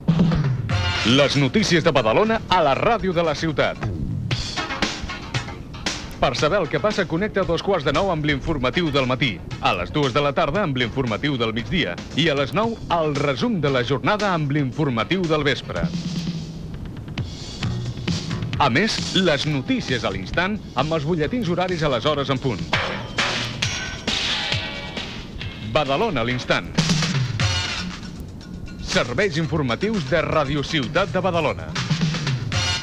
Promoció dels serveis informatius
FM